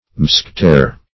Mousquetaire \Mous`que*taire"\, n. [F.]